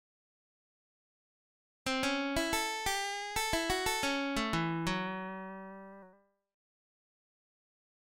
Blues lick > Blues